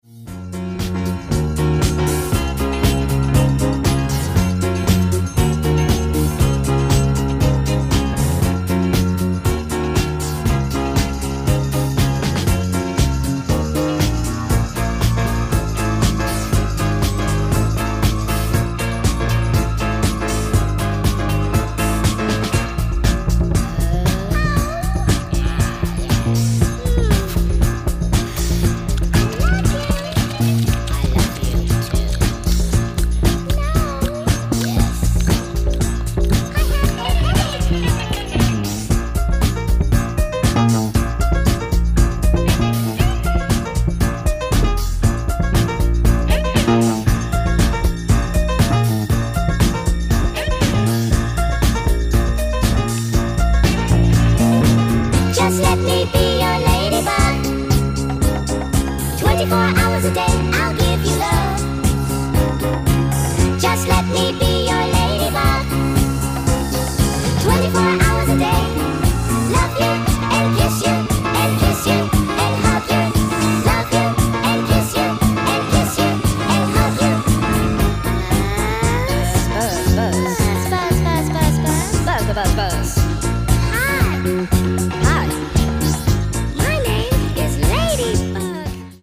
garage classic
Disco Mix
Disco Remix